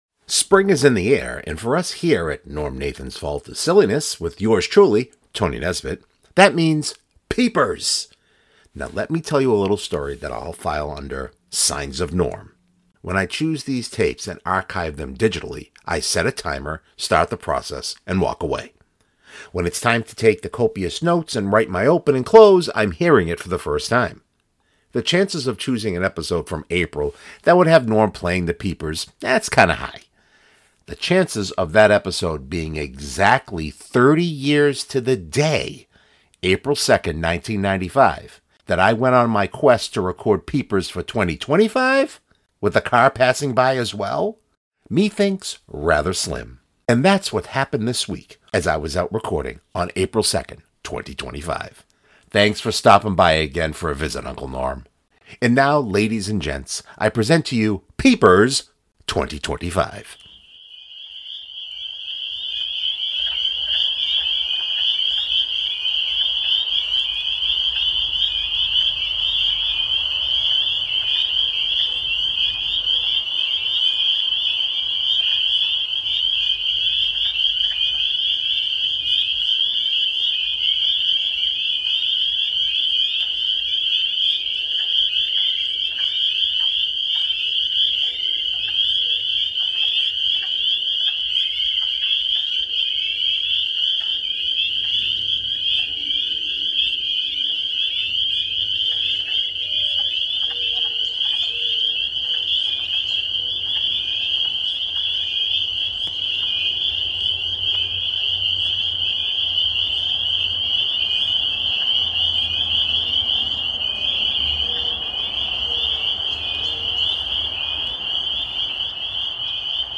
When I choose these tapes and archive them digitally, I set a timer, start the process and walk away.
With car passing by as well?